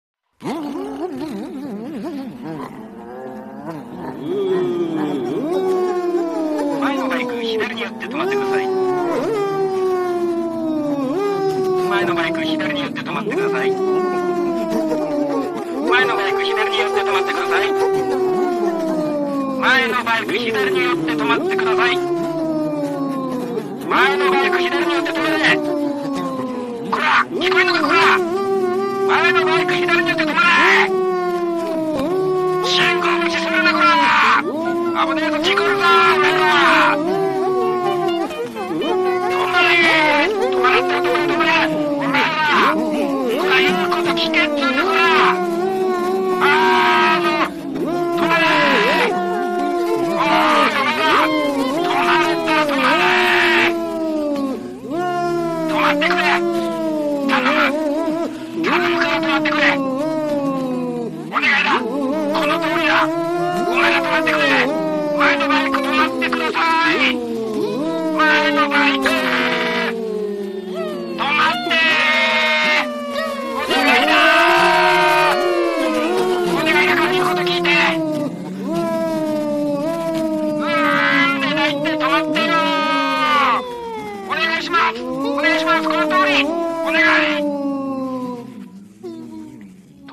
近所を通った珍走団とパトカー / 最初は優しいんです